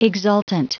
Prononciation du mot exultant en anglais (fichier audio)
Prononciation du mot : exultant
exultant.wav